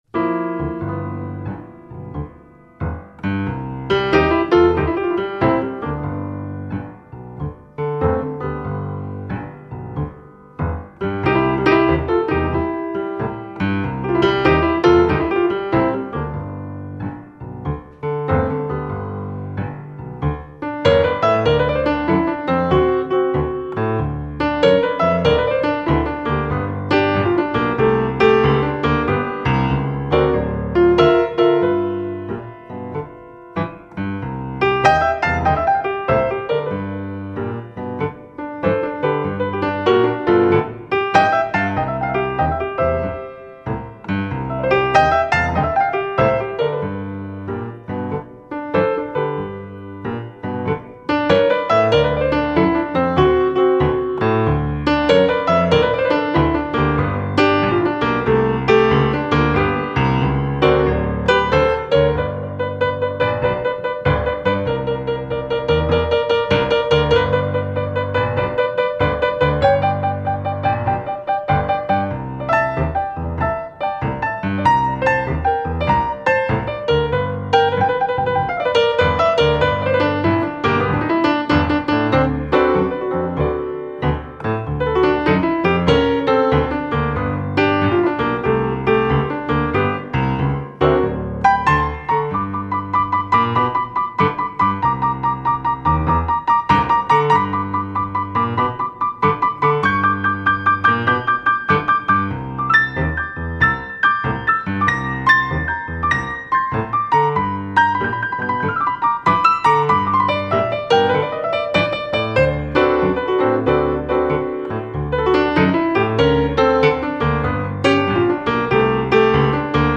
We play boogie woogie, blues, stride and a balad
• We play on two Steinway & Sons grand pianos